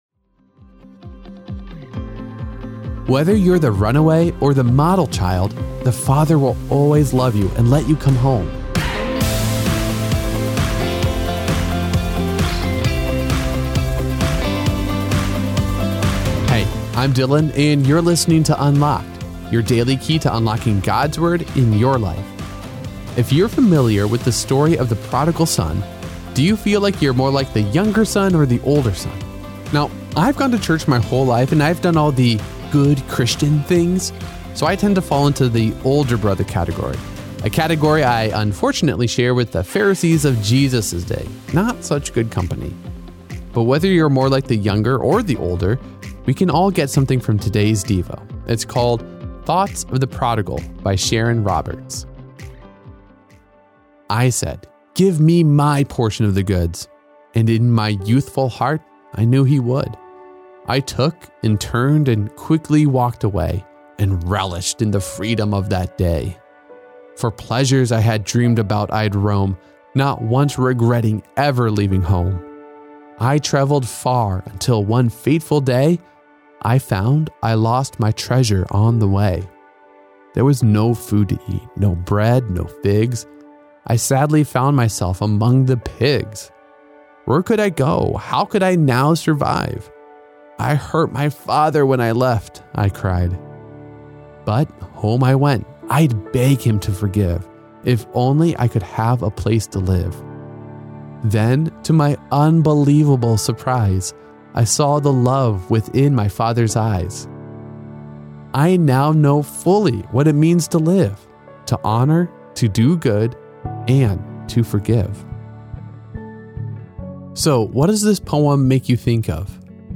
With daily devotions read by our hosts